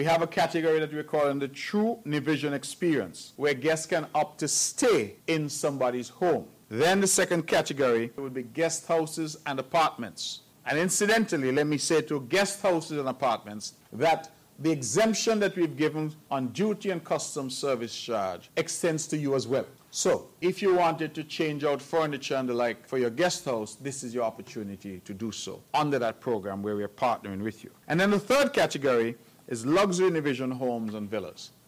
During his monthly press conference, Premier of Nevis and Minister of Tourism, Hon. Mark Brantley reminded persons of the three (3) categories which will be available: